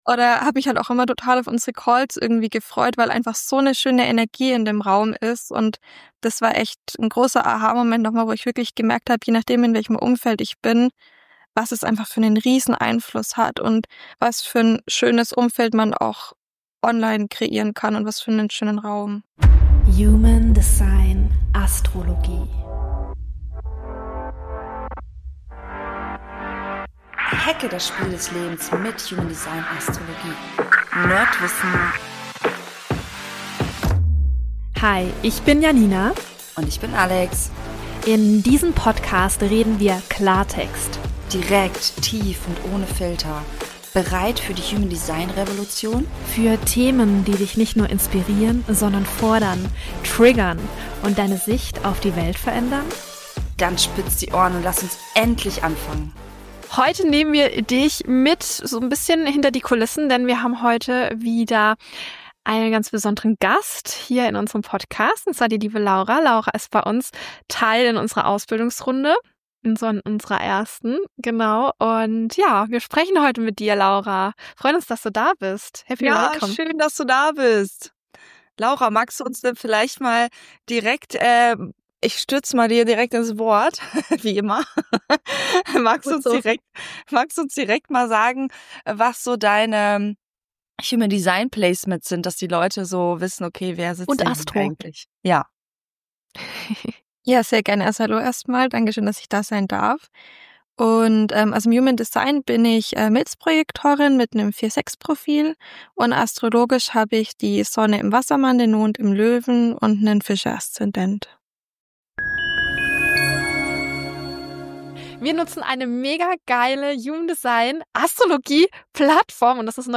Heute haben wir eine besondere Gästin